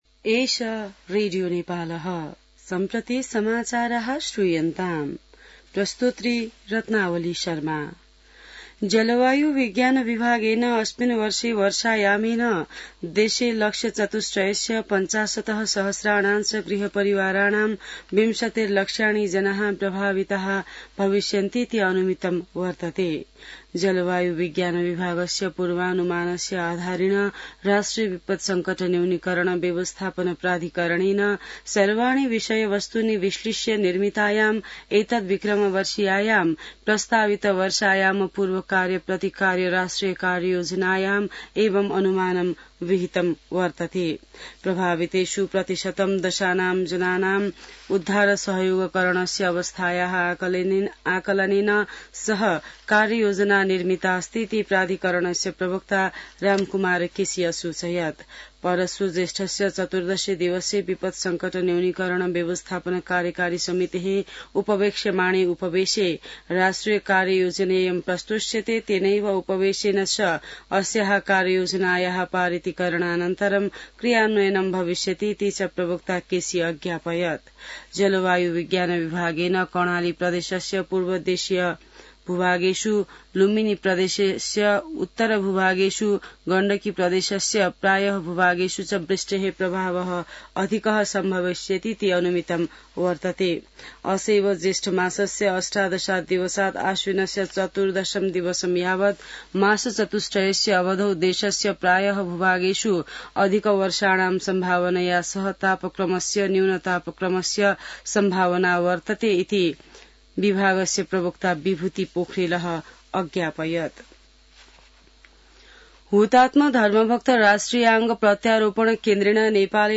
An online outlet of Nepal's national radio broadcaster
संस्कृत समाचार : १२ जेठ , २०८२